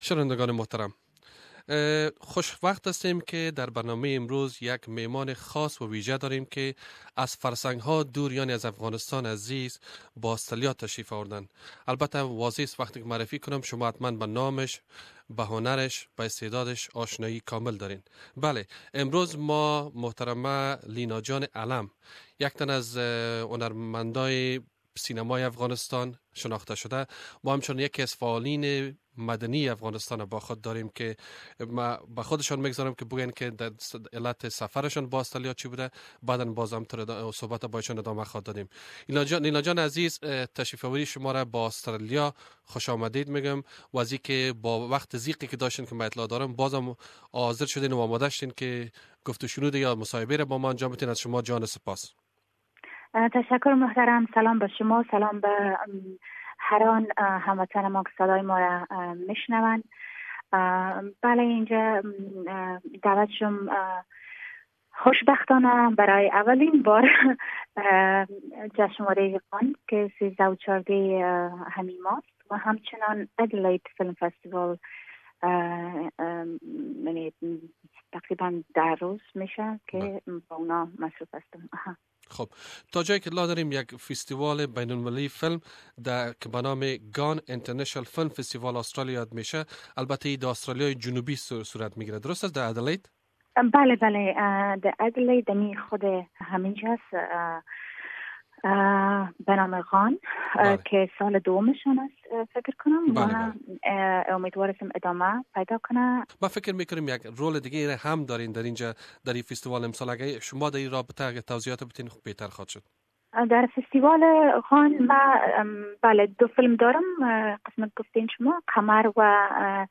مصاحبه با لینا آلم هنر پیشه نامور سینما و یکتن از فعالین مدنی در افغانستان